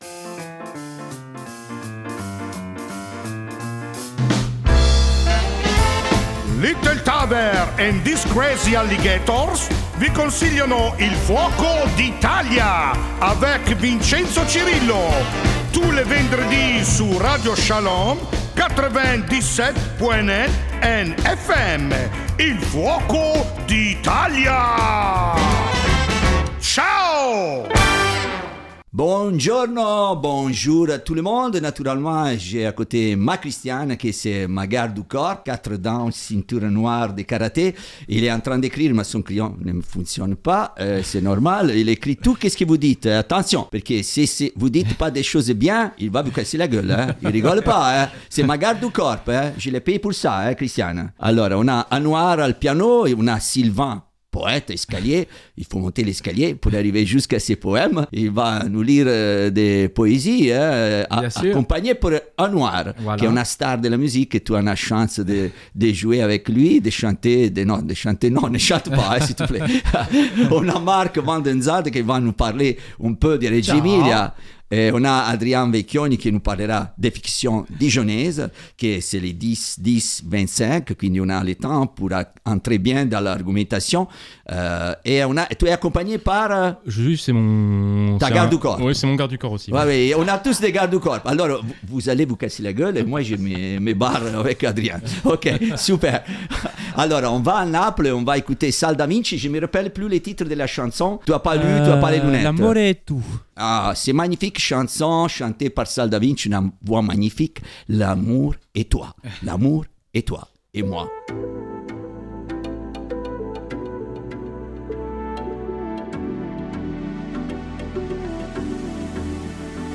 Votre rendez-vous aux couleurs de l'Italie c'est le vendredi à 17H en direct sur Radio Shalom Bourgogne -)
On écoute, on parle, on chante l'Italie mais pas que...